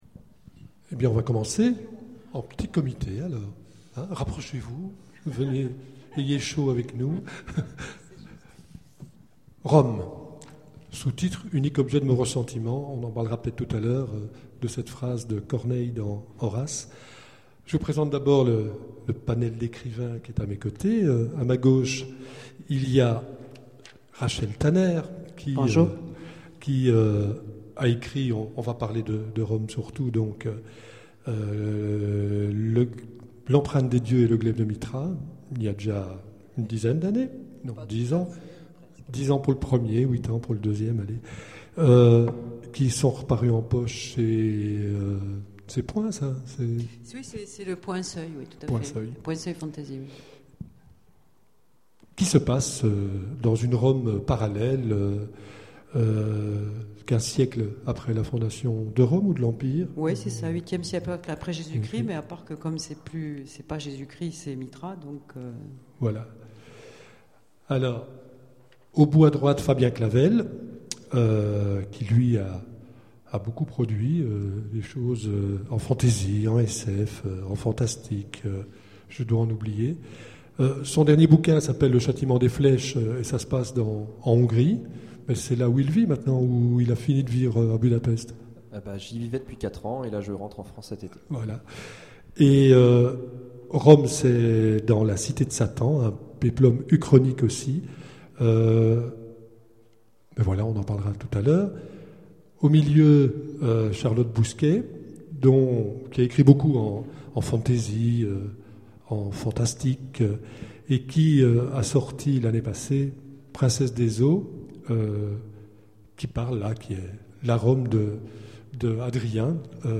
Imaginales 2011 : Conférence Rome, l'unique objet de mon ressentiment ?